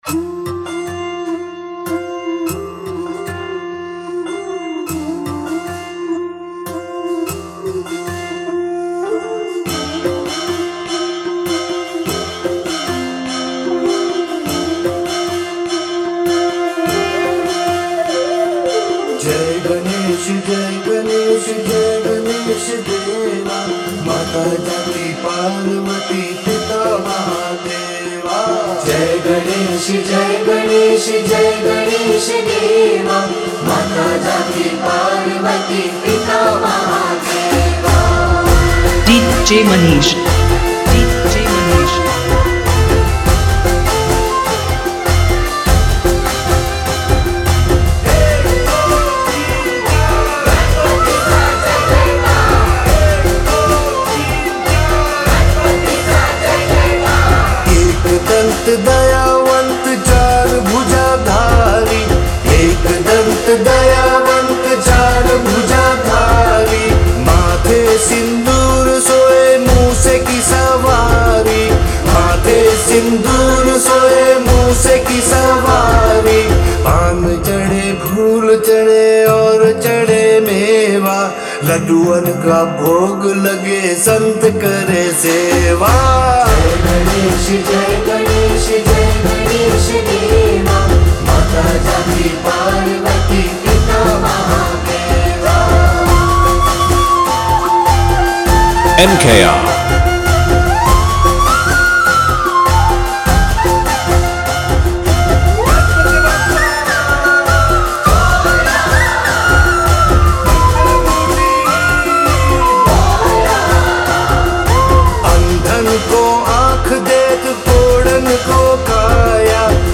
Ganesh Chaturthi Dj Remix Song